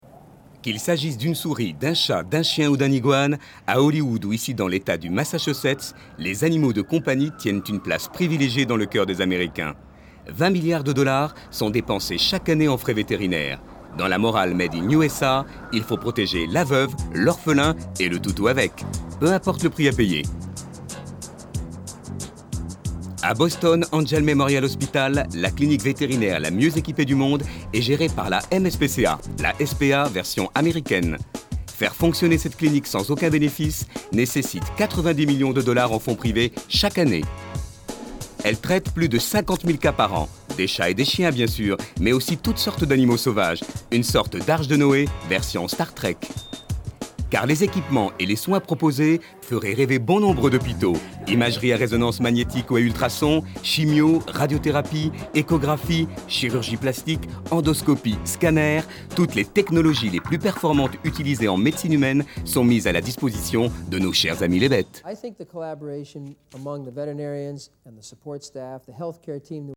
Bandes-son
Narration reportage Boston Hospital
Voix off